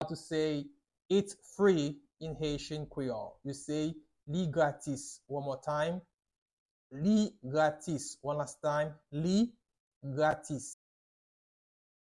Pronunciation:
Listen to and watch “Li gratis” audio pronunciation in Haitian Creole by a native Haitian  in the video below:
17.How-to-say-Its-Free-in-Haitian-Creole-–-Li-gratis-with-pronunciation.mp3